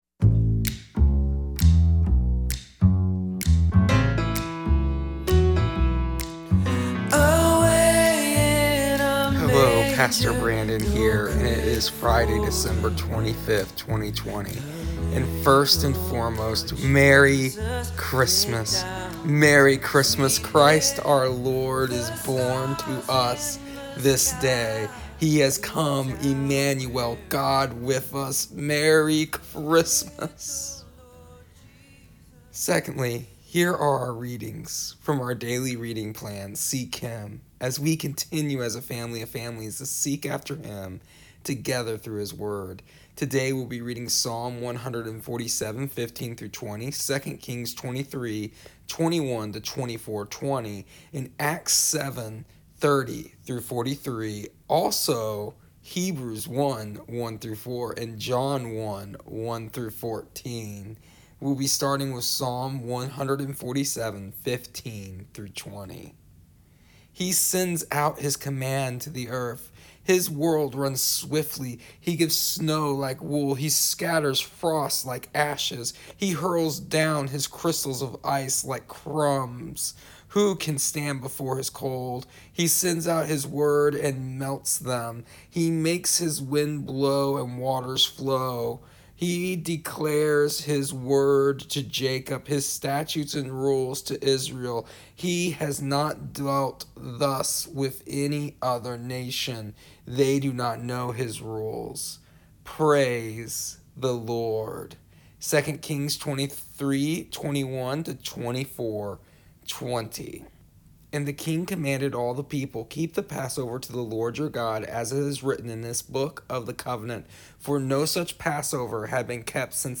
Also, here is the audio version of our daily readings from our daily reading plan Seek Him for December 25th, 2020.